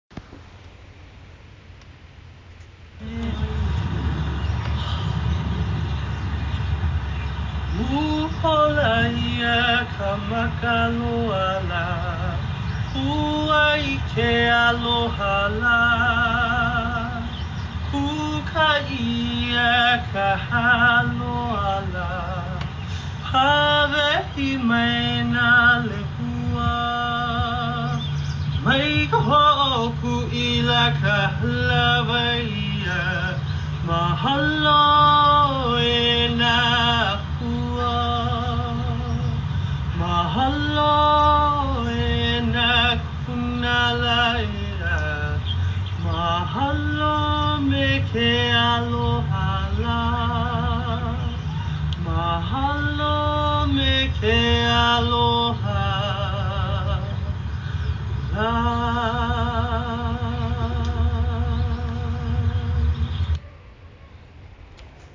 Here is a beautiful Hawaiian Oli we were gifted
And a Recording we found by an unknown Goddess on Online